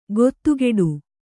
♪ gottugeḍu